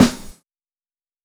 CG_Snr (65).WAV